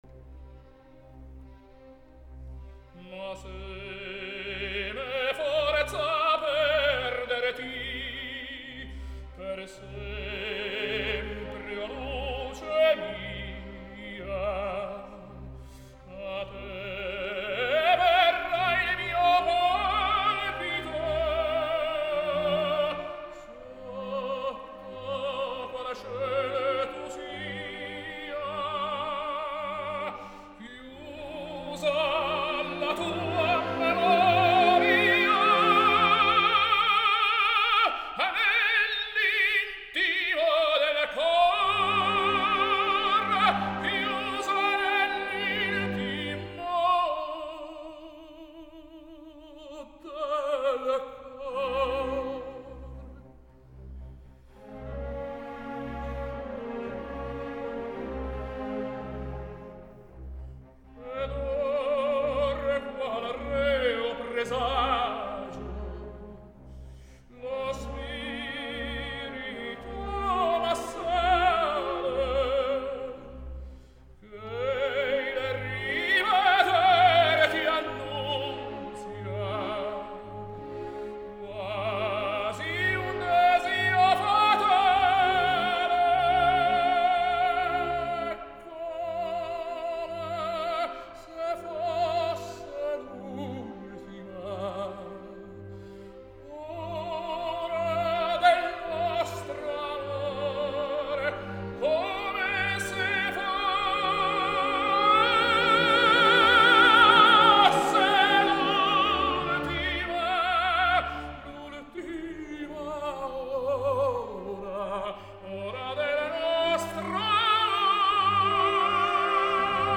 Italian tenor.
During the 70s, Pavarotti began a gradual expansion into spinto roles.